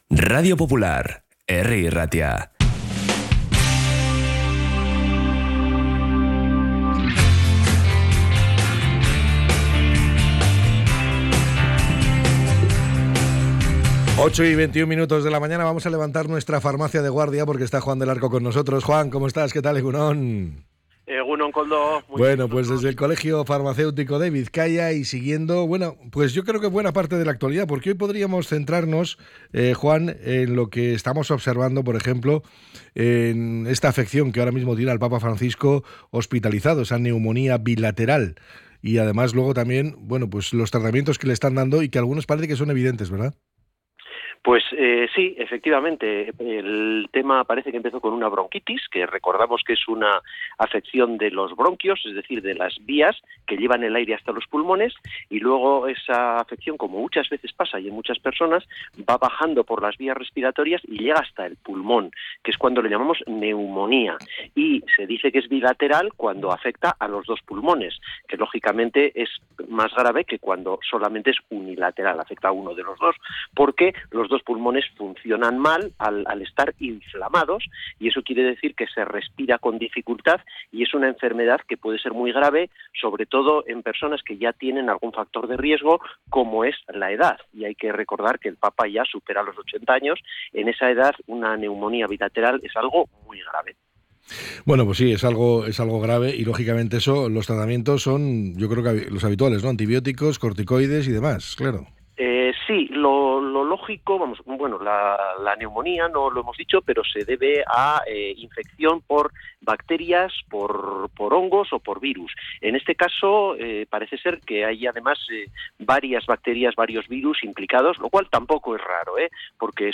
En Farmacia de Guardia analizamos una de las recientes problemáticas en términos farmacéuticos